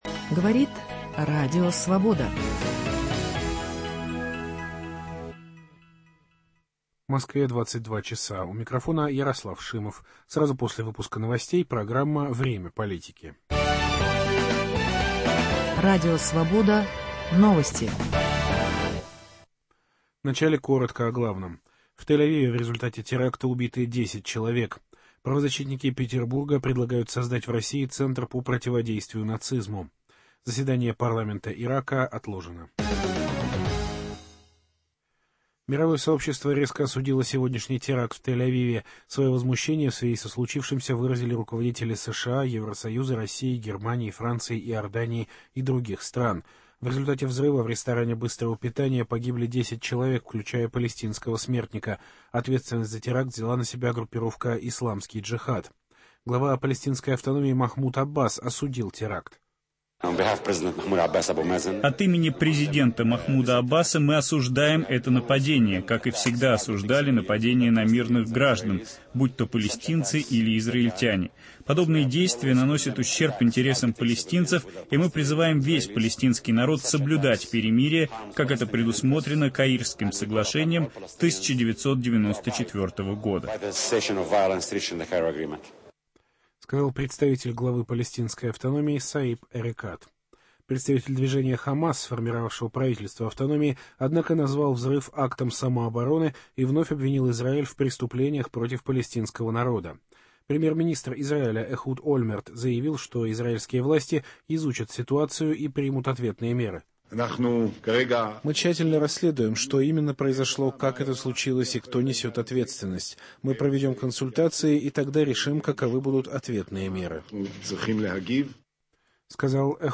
анализирует в прямом эфире